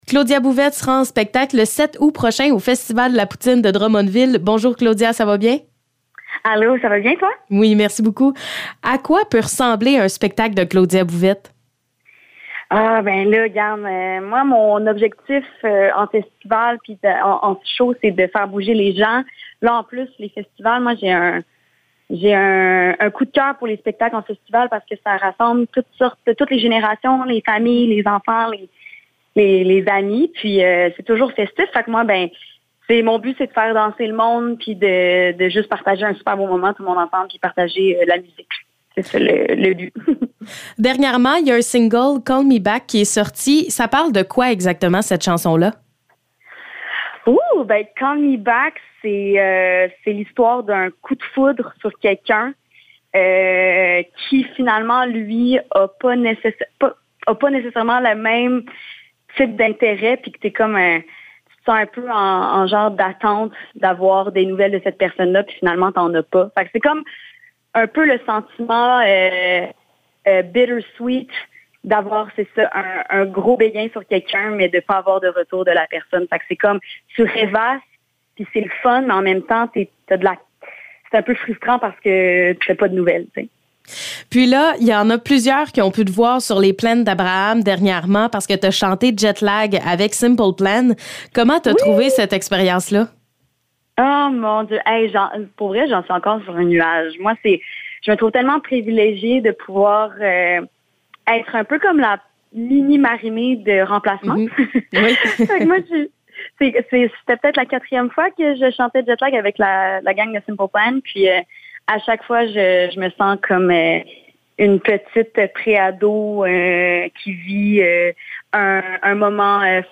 Entrevue avec Claudia Bouvette